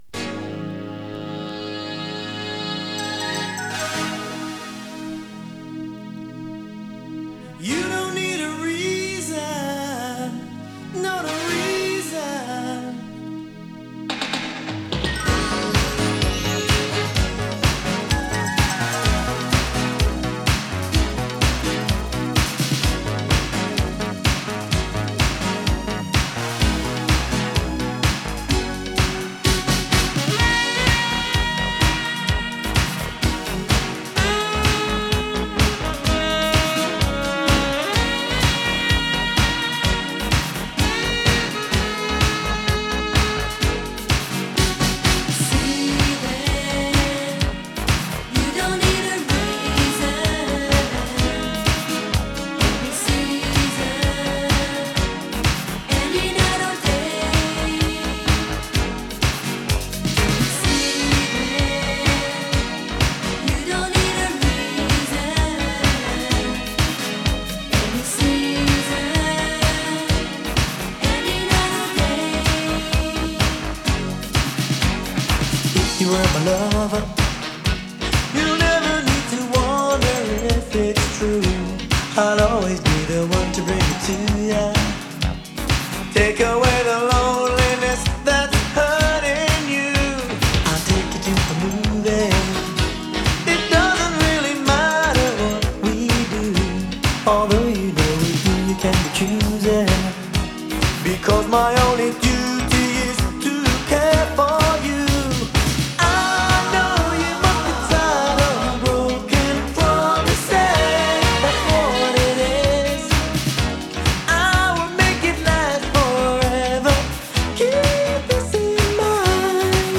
モダンソウル
切ないメロディーと爽やかなトラックが溶け合うアーバン・ダンサー！